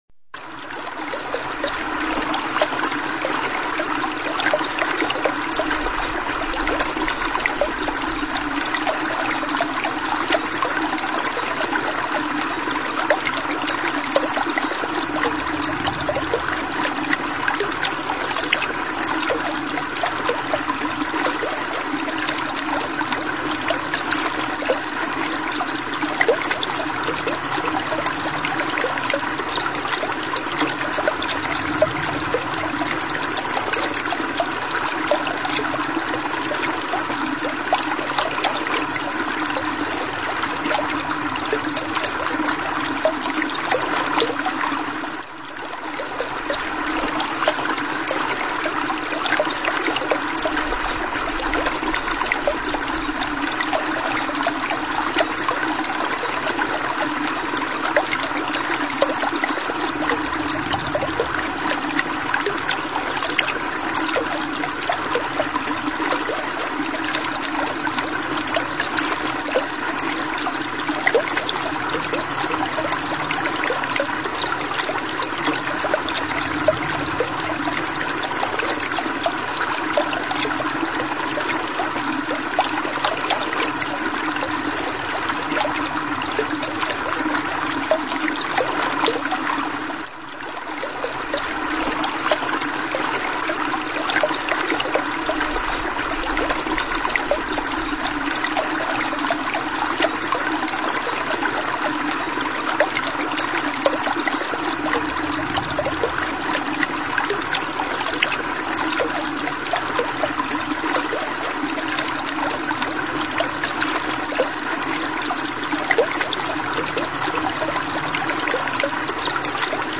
Музыка-релакс для занятий
zvuki_reca.mp3